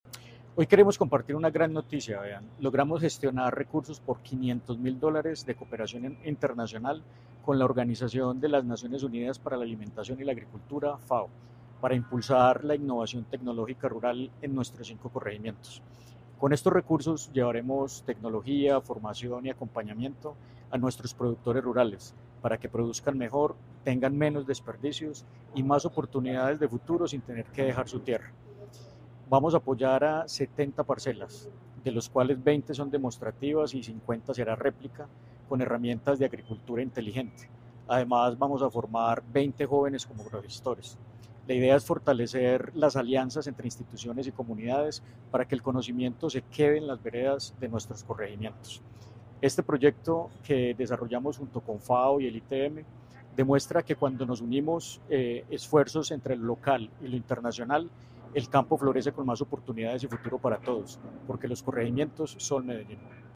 Declaraciones del gerente de Corregimientos, Andrés Felipe López Vergara
Declaraciones-del-gerente-de-Corregimientos-Andres-Felipe-Lopez-Vergara.mp3